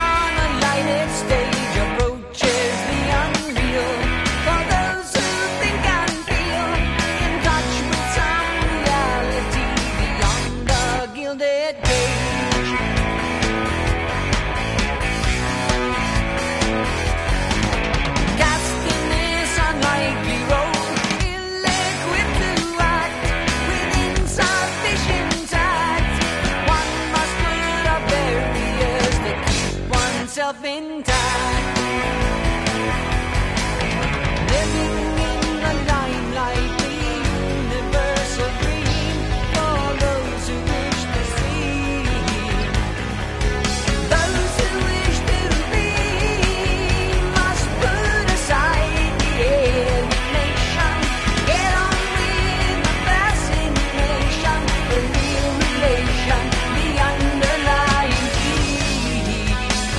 Canadian progressive rock band